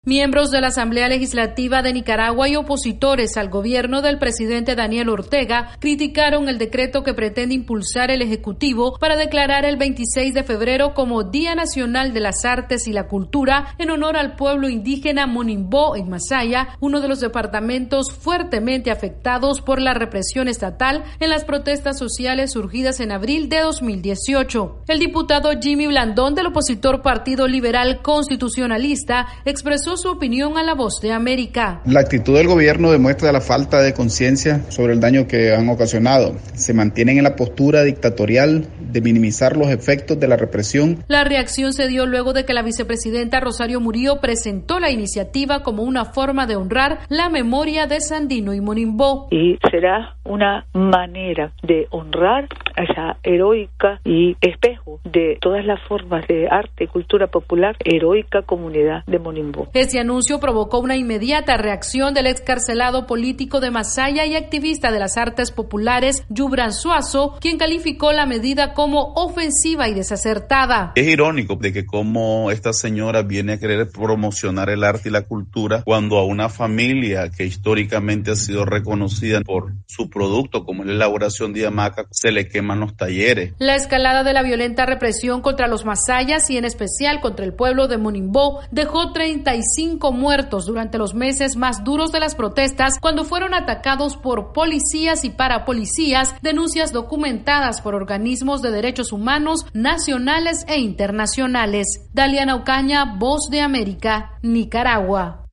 VOA: Informe de Nicaragua